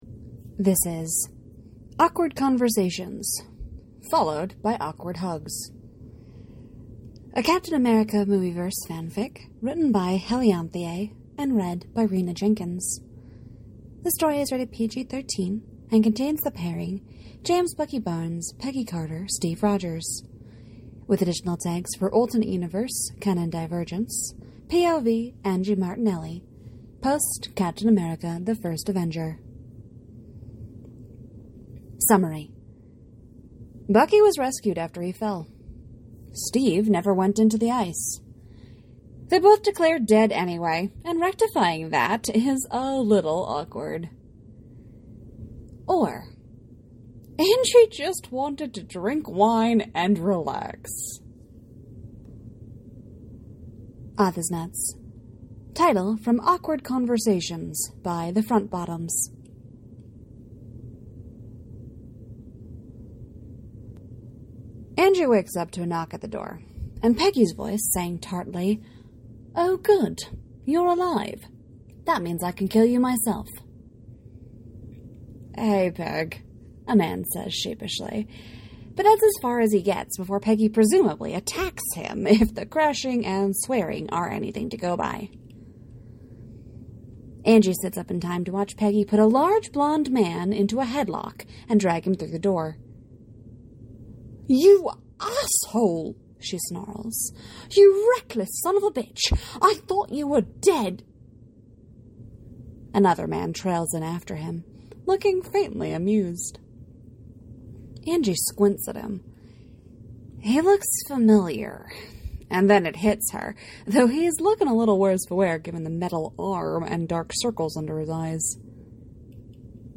comment to the podficcer here